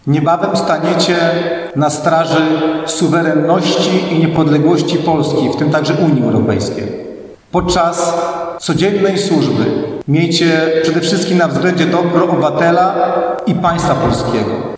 W czwartek (20.03) odbyło się uroczyste ślubowanie.